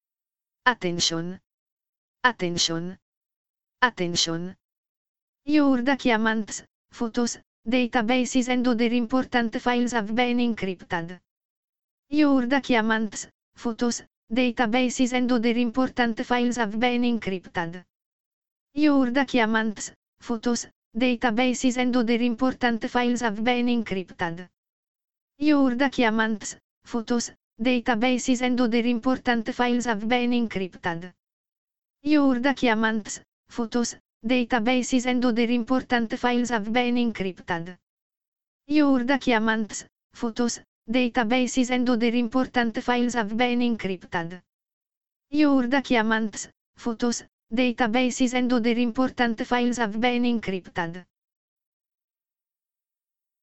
This script allows the Cerber talking to his victim, as you can listen to the following audio files: "